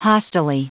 Prononciation du mot hostilely en anglais (fichier audio)
Prononciation du mot : hostilely